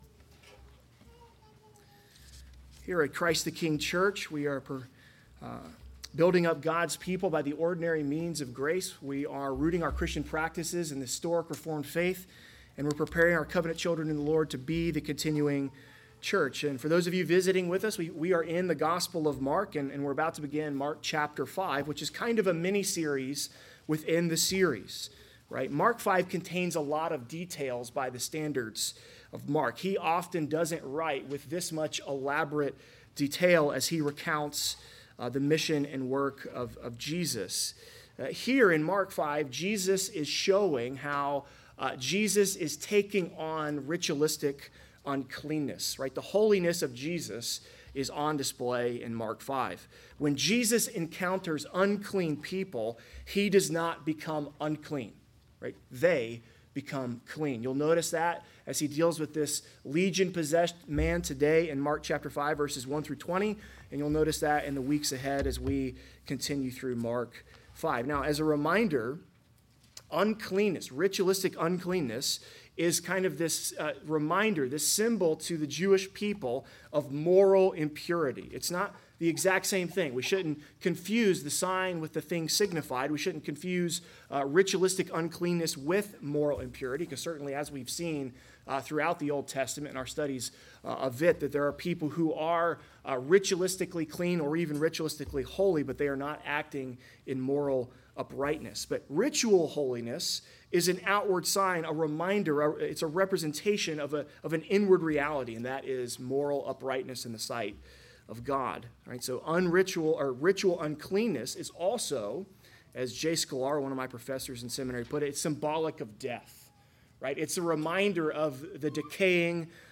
Mark 5.1-20 Service Type: Sunday Worship Big Idea